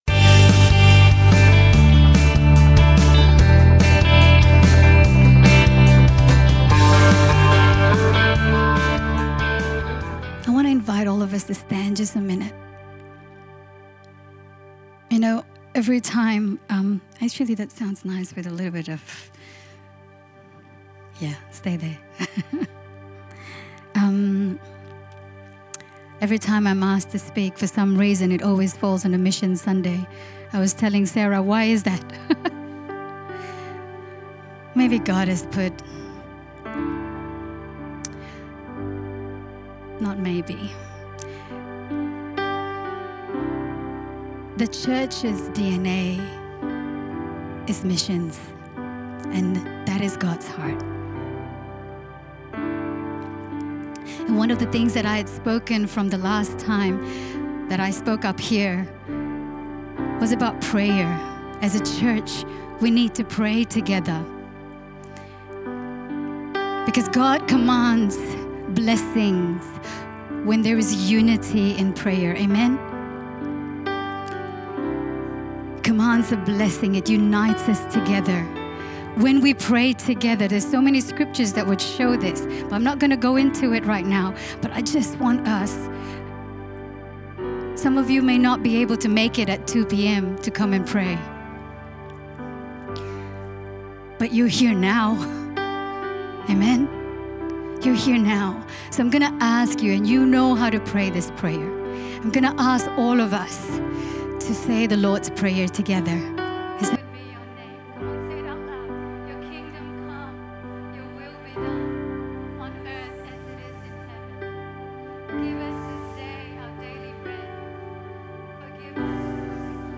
Sermons | Firstlight Church